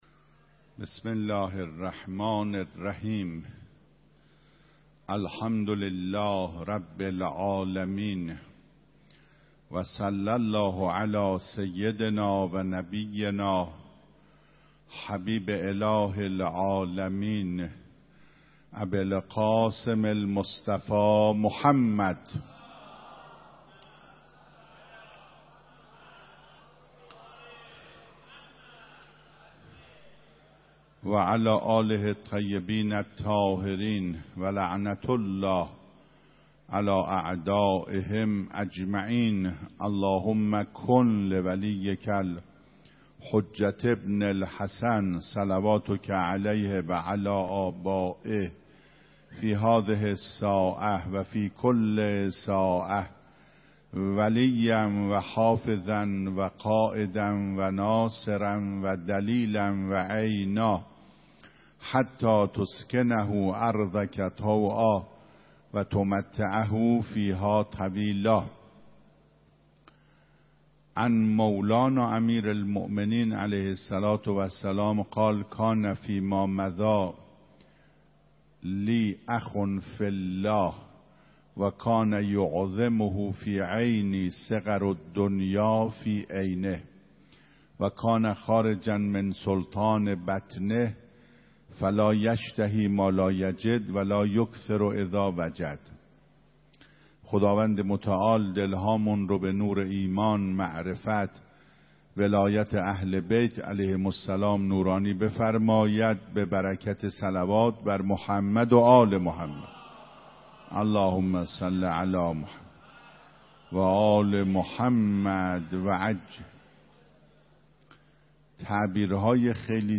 سخنرانی خود در آستان حرم حضرت معصومه (س)
صوت کامل این سخنرانی را در زیر بشنوید: